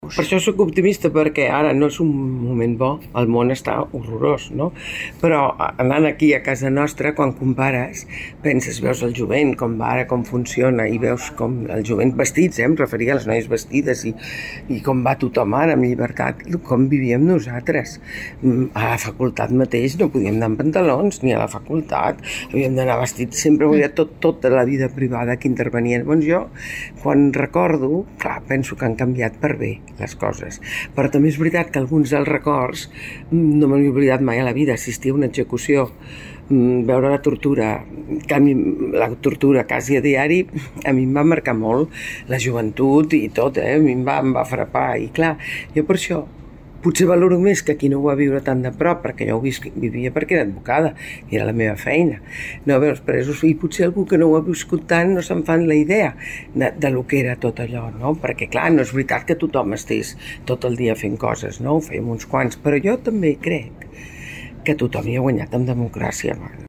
Magda Oranich, advocada i autora 'Totes les batalles'